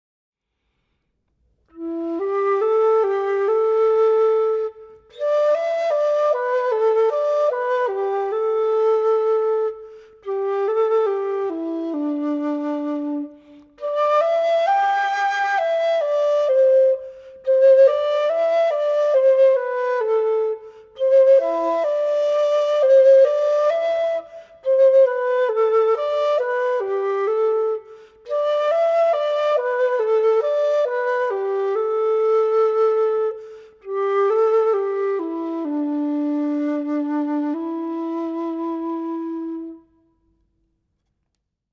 箫的历史悠久绵长，音色圆润典雅，是中国优秀传统音乐文化的重要组成部分。